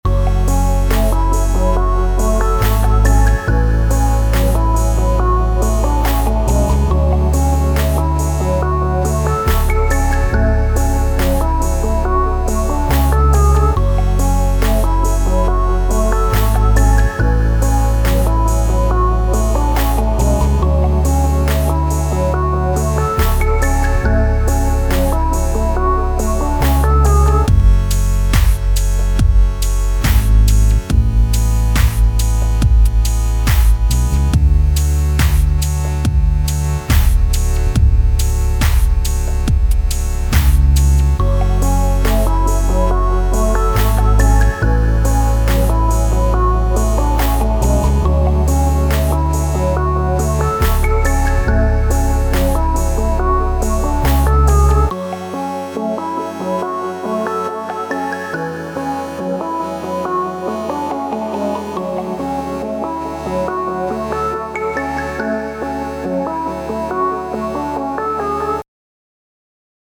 Home > Music > Beats > Smooth > Medium > Dreamy